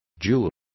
Complete with pronunciation of the translation of jew.